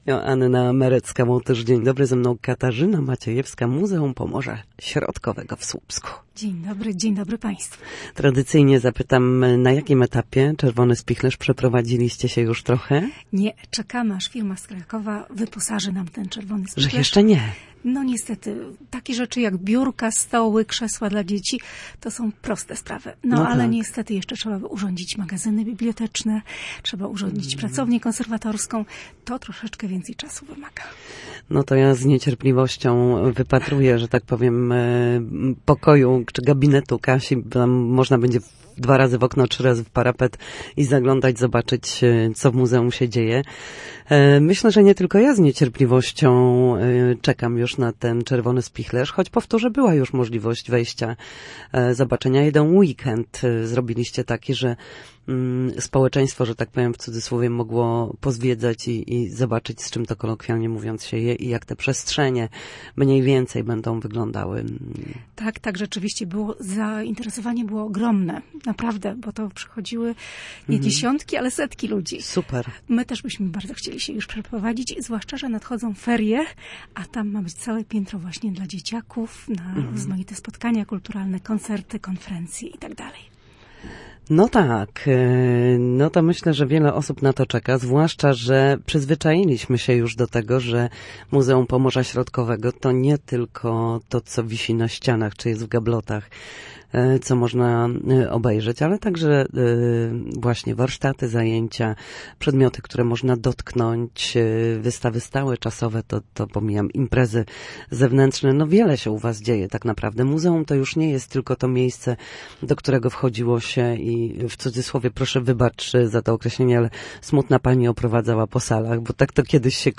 Tradycyjnie od kilku lat słupskie Muzeum przygotowało atrakcyjną ofertę na okres ferii zimowych dla dzieci i młodzieży z regionu. Gościem Studia Słupsk